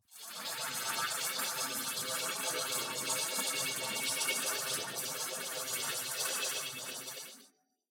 gravity_gun.wav